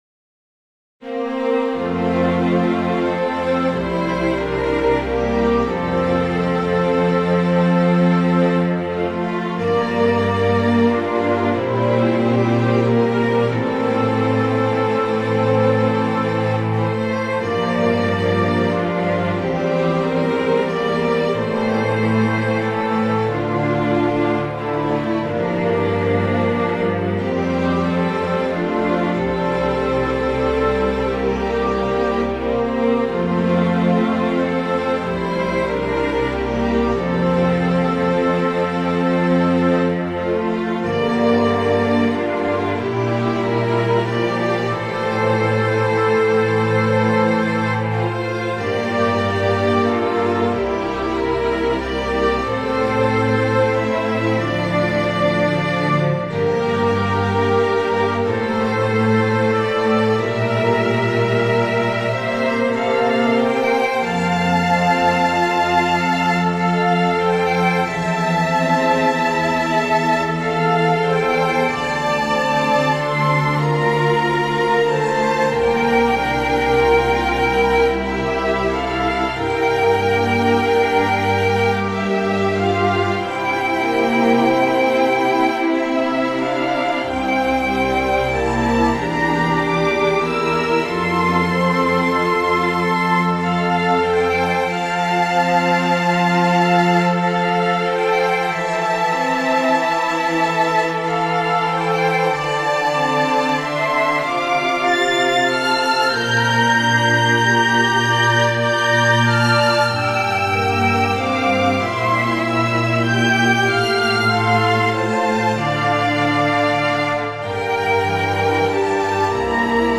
クラシックロング穏やか